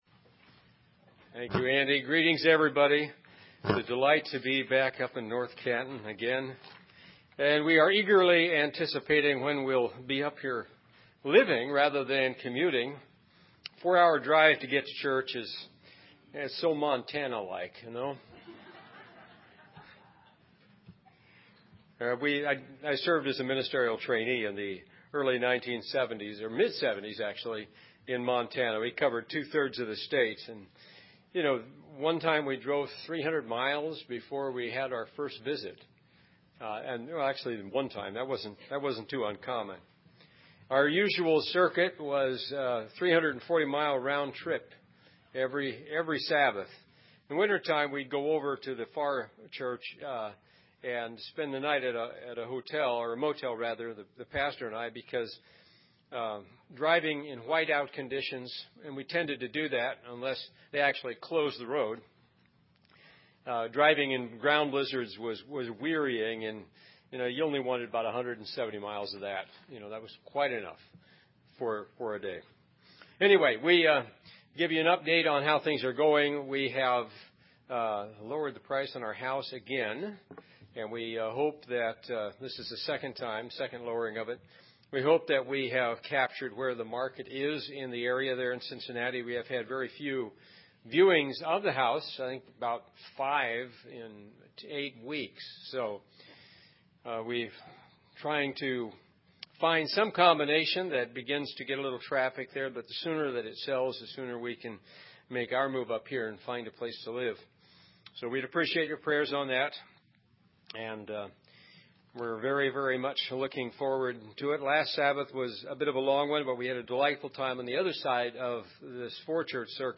UCG Sermon Studying the bible?
Given in North Canton, OH